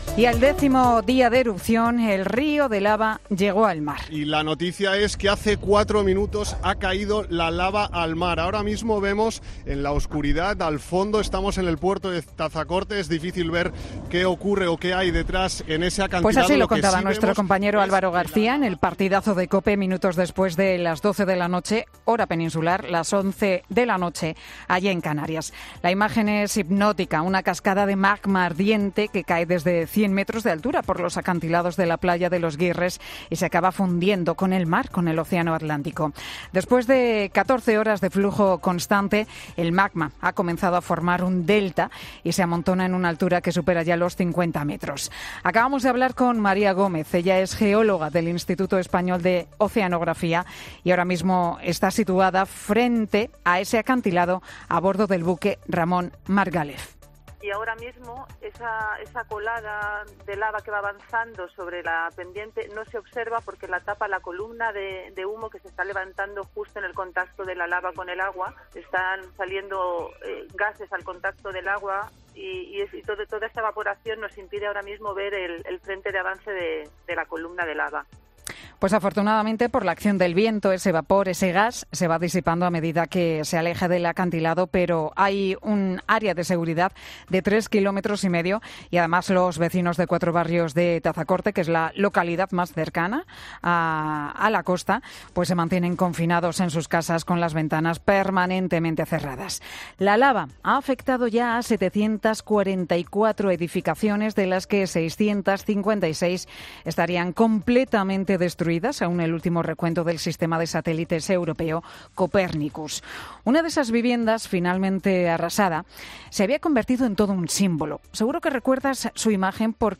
Monólogo de Pilar García Muñiz Pilar García Muñiz sobre la casa milagro: "Simbolizaba esperanza.